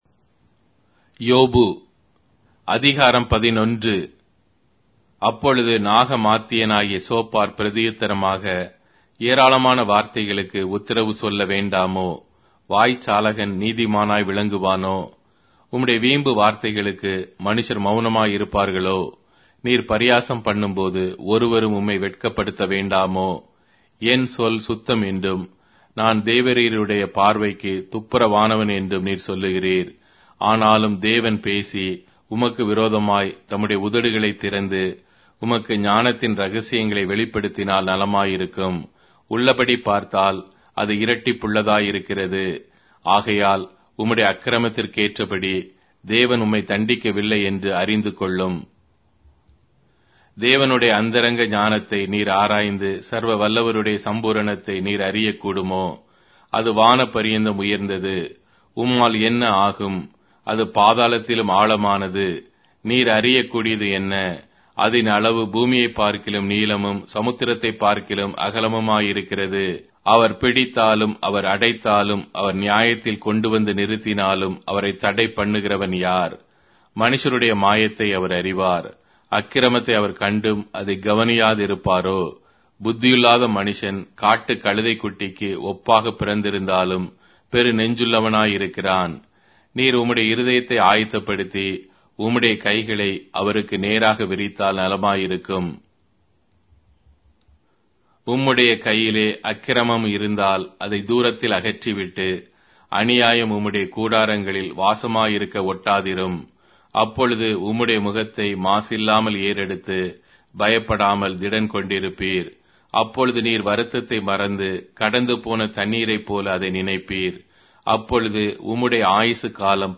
Tamil Audio Bible - Job 26 in Pav bible version